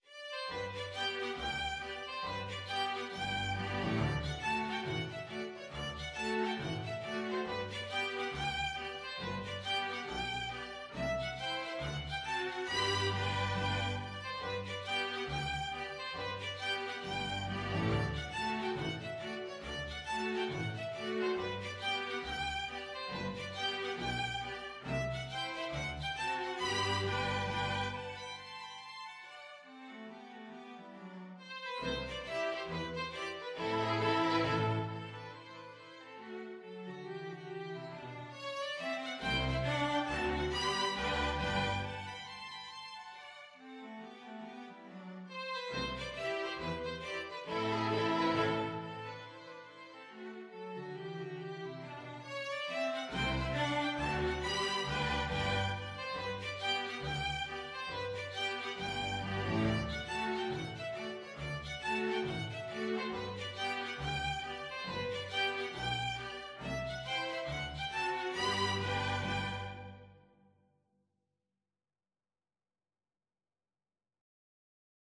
Violin 1Violin 2ViolaCelloDouble Bass
G major (Sounding Pitch) (View more G major Music for String Ensemble )
With a swing =c.69
2/2 (View more 2/2 Music)
String Ensemble  (View more Intermediate String Ensemble Music)
Traditional (View more Traditional String Ensemble Music)
Irish